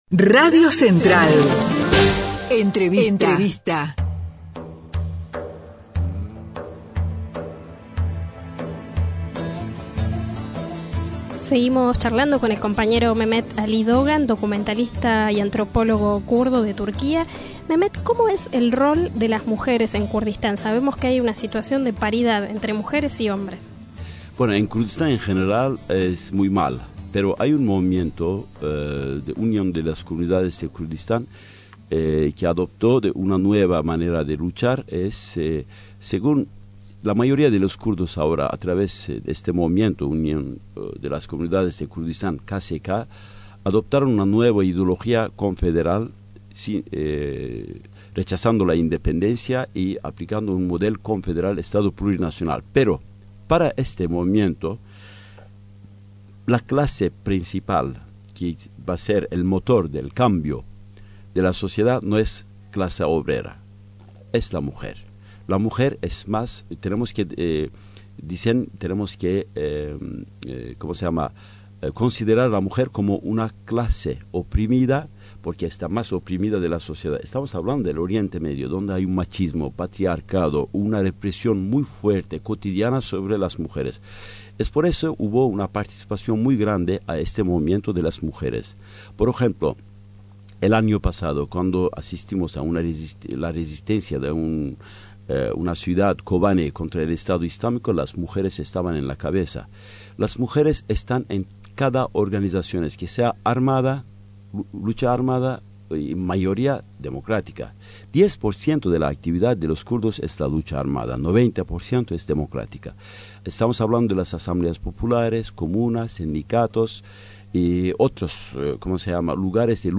antropólogo y documentalista kurdo